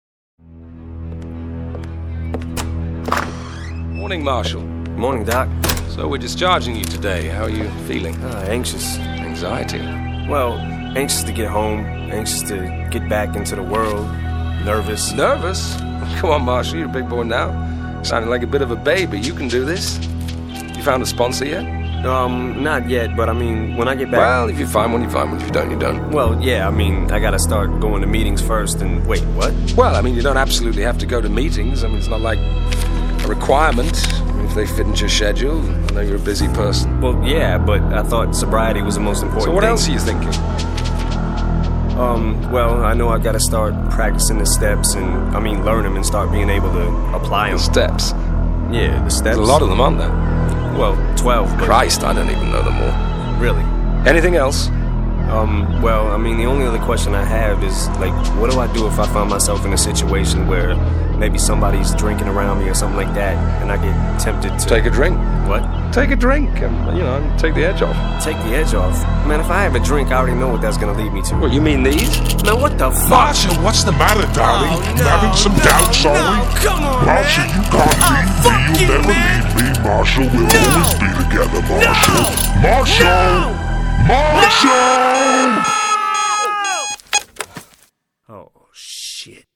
Hip Hop, Rap